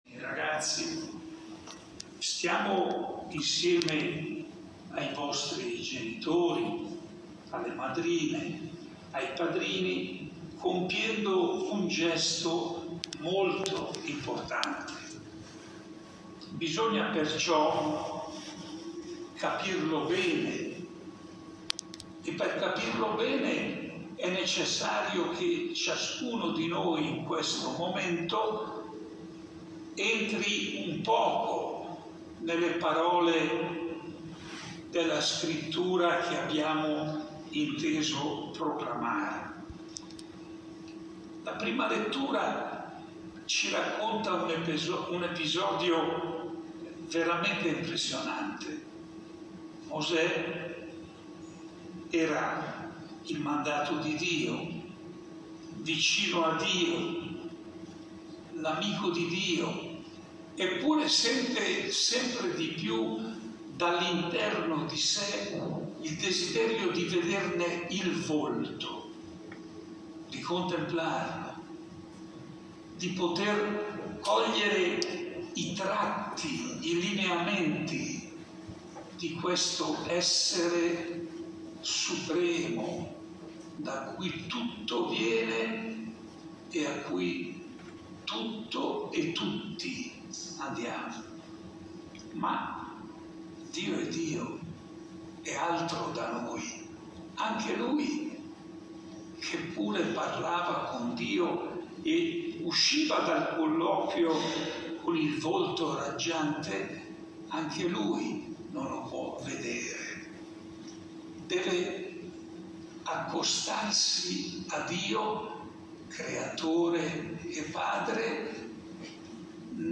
Proponiamo la registrazione audio dell’omelia pronunciata dal cardinale Scola il 9 ottobre 2022, in occasione del conferimento del sacramento della Confermazione presso la chiesa di San Giorgio Martire ad Imberido.